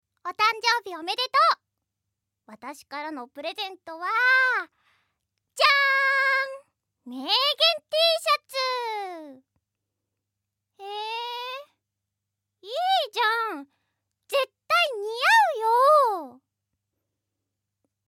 Voice
自己紹介